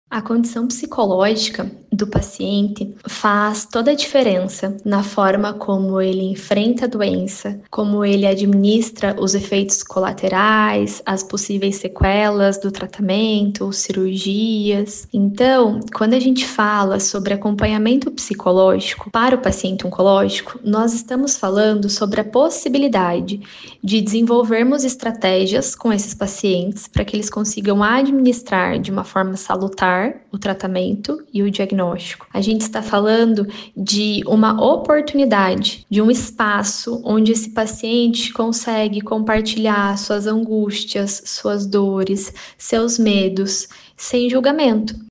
Ela explica que é essencial que o paciente oncológico receba suporte psicológico desde o início.